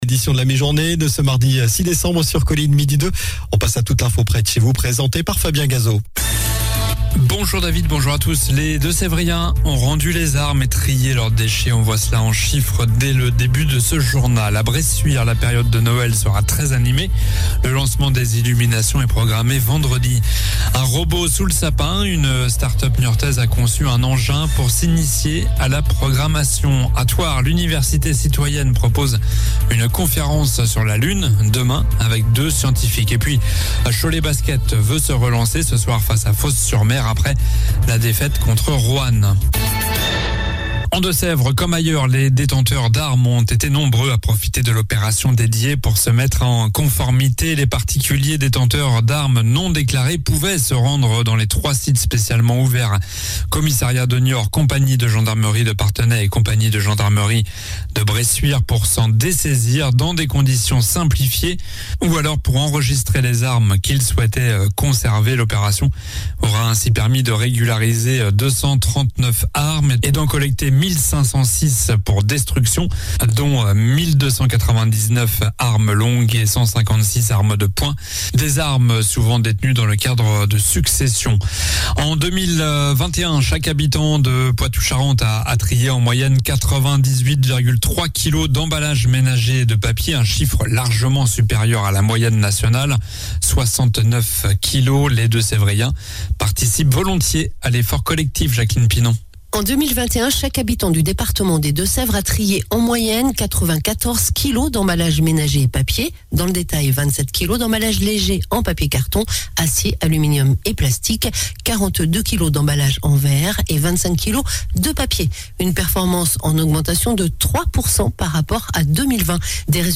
Journal du mardi 06 décembre (midi)